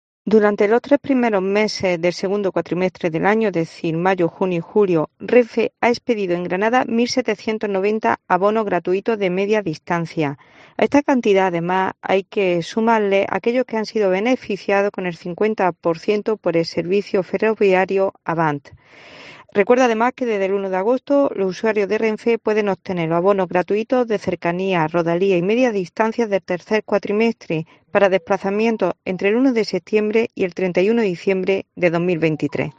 Inmaculada López Calahorro, Subdelegada del Gobierno en Granada